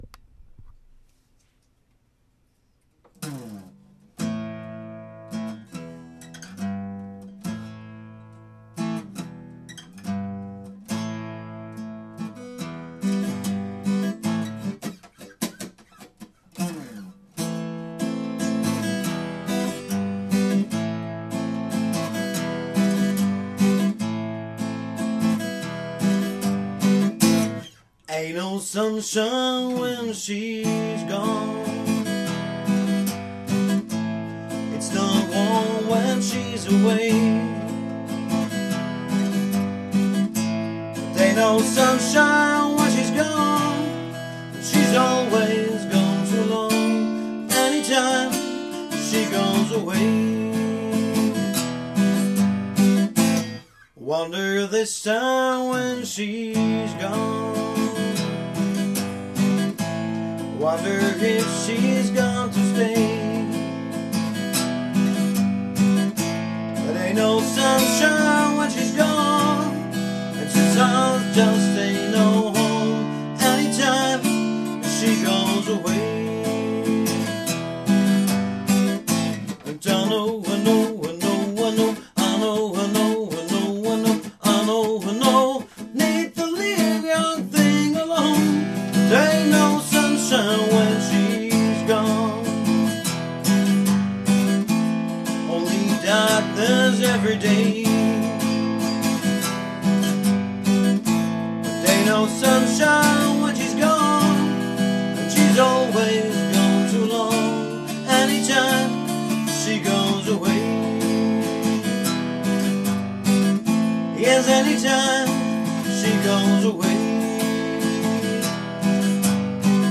Handmade Music, Livemusic mit Gitarre und Gesang
• Unplugged
• Coverband